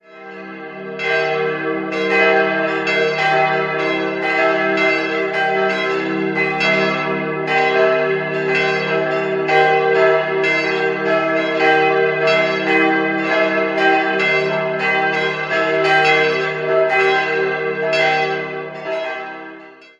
Die erhöht am Ortsrand liegende Pfarrkirche Mariä Himmelfahrt wurde unter Einbeziehung des gotischen Turmes im Jahr 1746 erbaut. 4-stimmiges Geläut: e'-g'-c''-e''(+) Das dreistimmige Hauptgeläut wurde 1962/63 von Rudolf Perner in Passau gegossen.